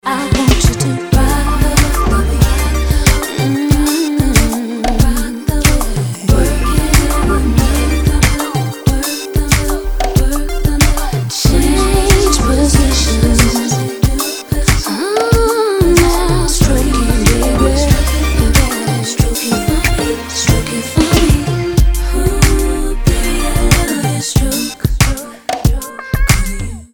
• Качество: 256, Stereo
красивые
RnB
красивый женский голос